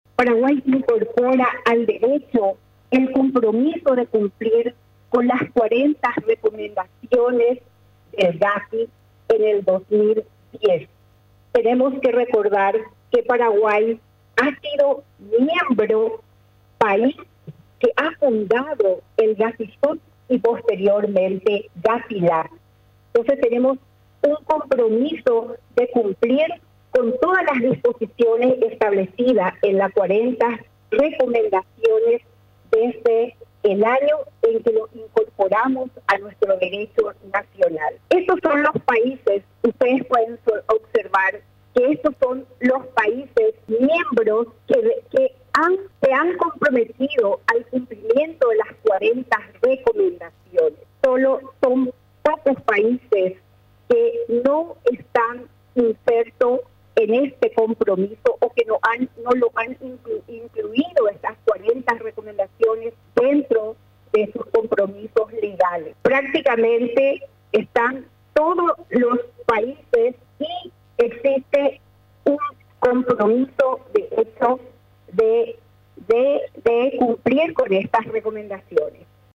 La ministra de la Secretaría de Prevención del Lavado de Dinero y Bienes (SEPRELAD) Epifanía González, resaltó durante la presentación del proyecto de Ley contra el crimen organizado a los legisladores del Congreso Nacional, que Paraguay cumplirá su compromiso de combatir las acciones de los narcotraficantes y contrabandistas.